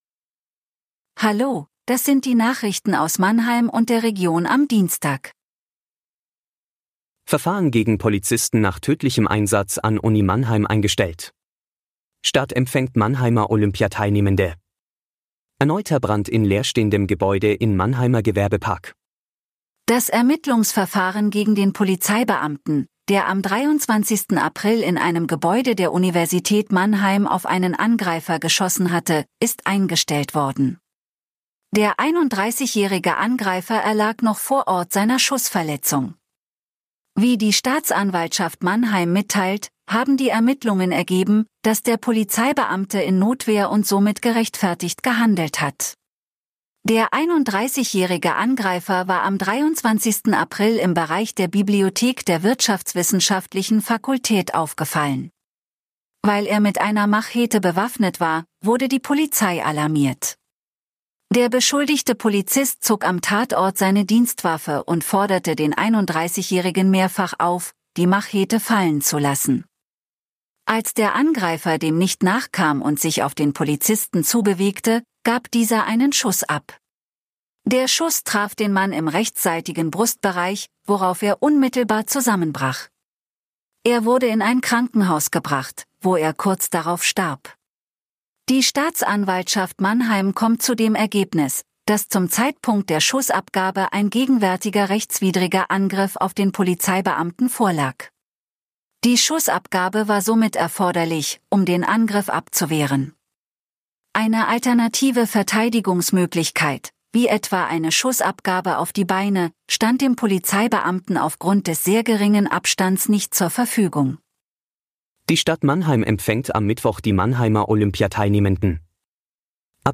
Der Nachrichten-Podcast des MANNHEIMER MORGEN